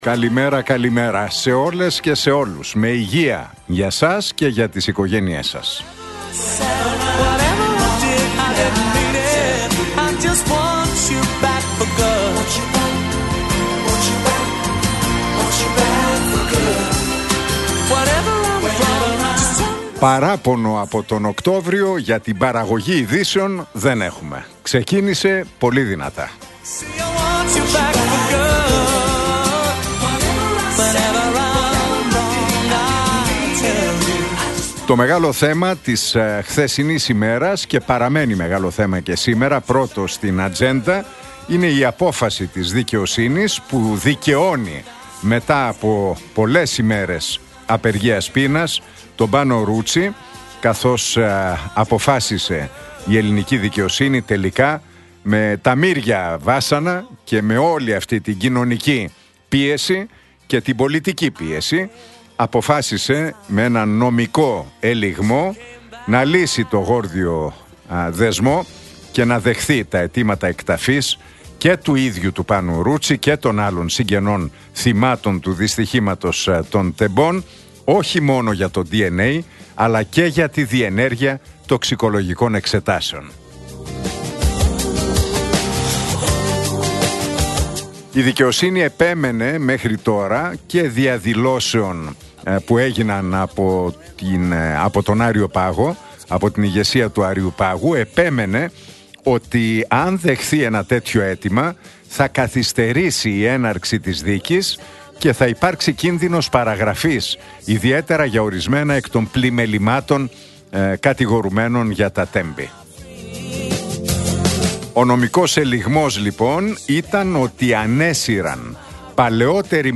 Ακούστε το σχόλιο του Νίκου Χατζηνικολάου στον ραδιοφωνικό σταθμό Realfm 97,8, την Τρίτη 7 Οκτώβριου 2025.